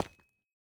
Minecraft Version Minecraft Version 21w07a Latest Release | Latest Snapshot 21w07a / assets / minecraft / sounds / block / calcite / step6.ogg Compare With Compare With Latest Release | Latest Snapshot
step6.ogg